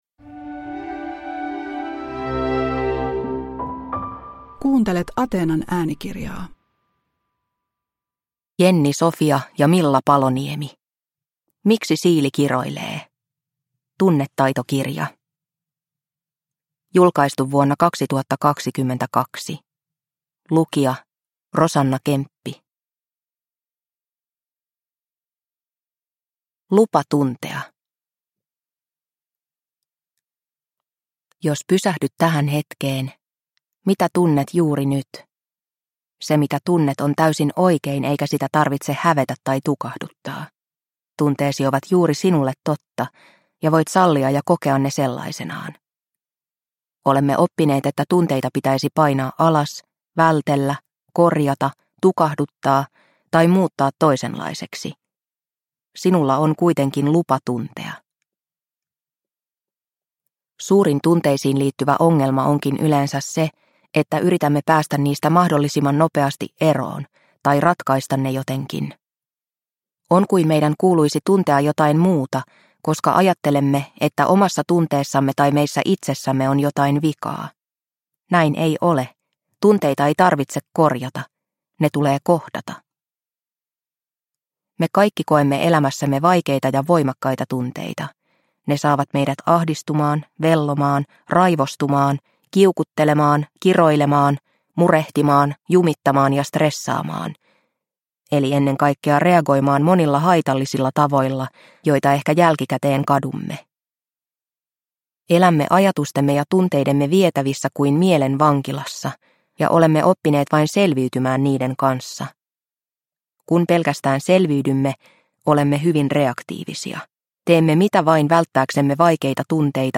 Miksi Siili kiroilee? – Ljudbok – Laddas ner